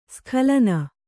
♪ skhalana